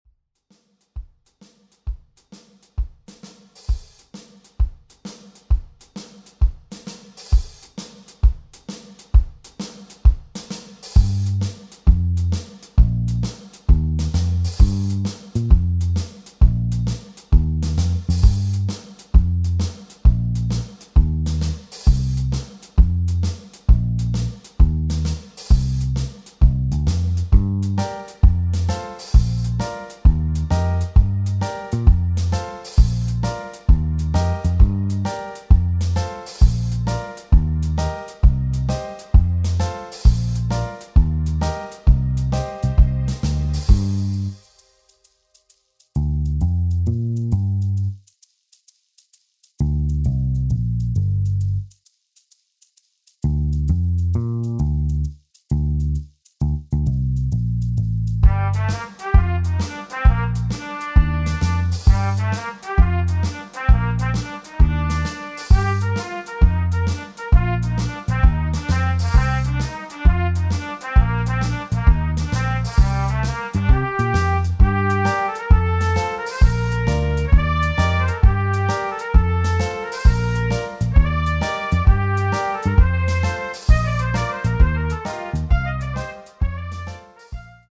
• Das Instrumental beinhaltet NICHT die Leadstimme